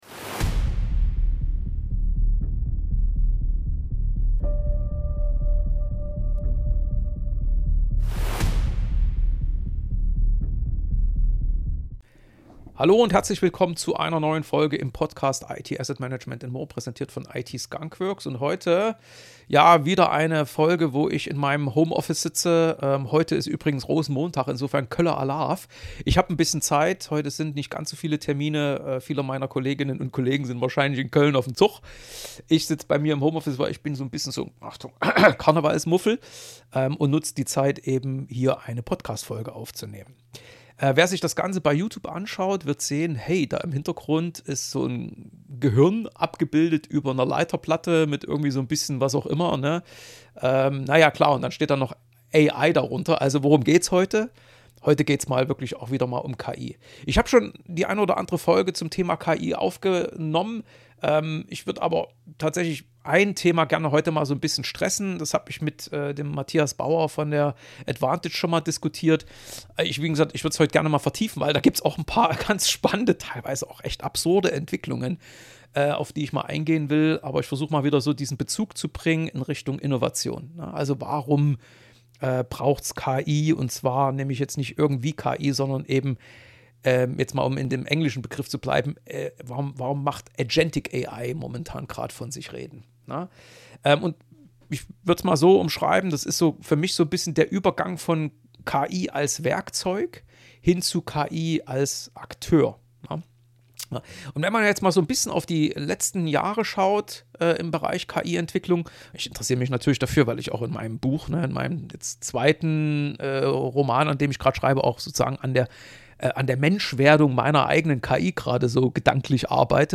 Beschreibung vor 2 Monaten In dieser Rosenmontags-Homeoffice-Episode geht’s um Innovation – und warum Agentic AI gerade so viel Aufmerksamkeit bekommt: Wir erleben den Übergang von KI als Werkzeug hin zu KI als Akteur. Also weg vom Chatbot, der nur reagiert, hin zu Systemen, die selbst planen, priorisieren, ausführen und ihre Zwischenschritte prüfen.